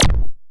bay_door_close.wav